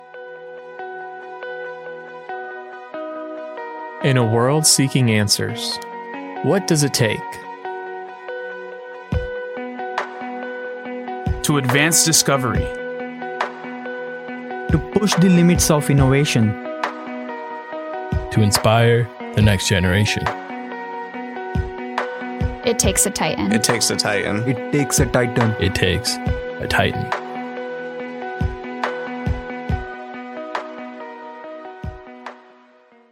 University Audio Ad Sample